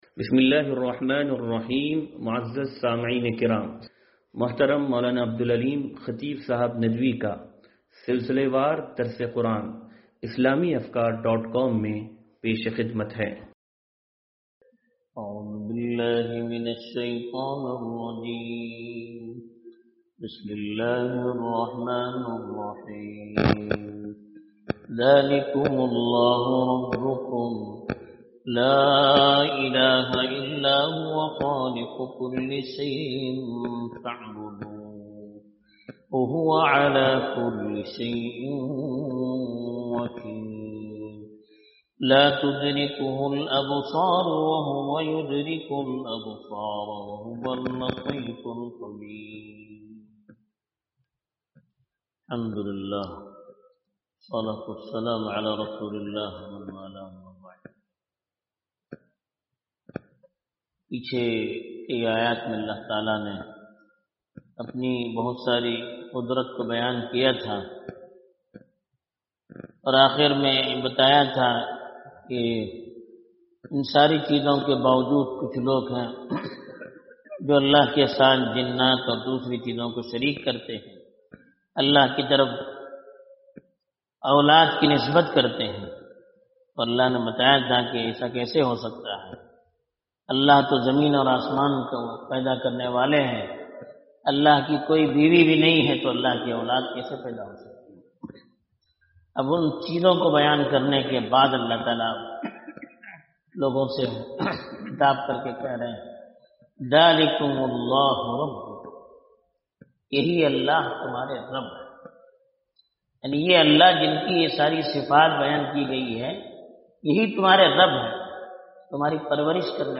درس قرآن نمبر 0554